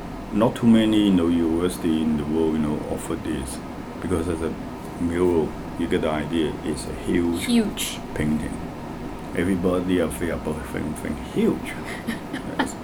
S1 = Hong Kong male S2 = Malaysian female
S2 : @@@@ Intended Words : afraid Heard as : are feel Discussion : There is no [r] or final [d] in afraid .